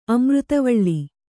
♪ amřtavaḷḷi